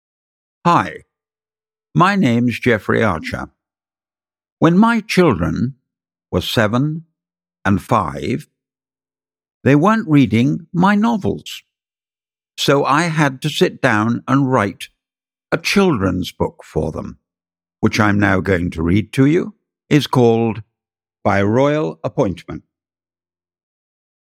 By Royal Appointment (EN) audiokniha
Ukázka z knihy
• InterpretJeffrey Archer